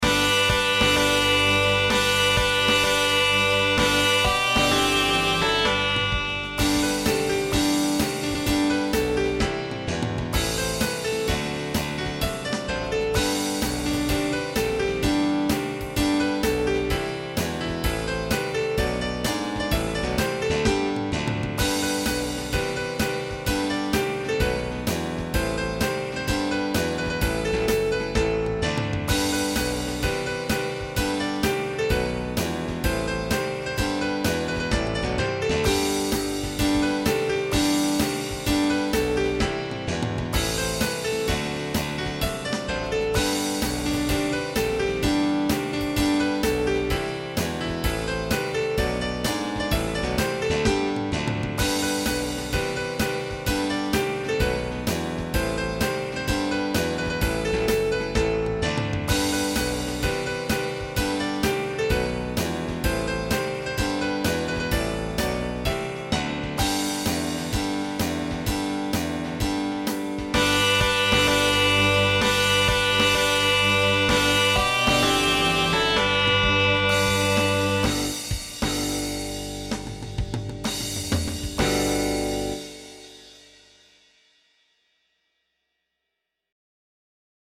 An old favorite Christmas song.
Recording from MIDI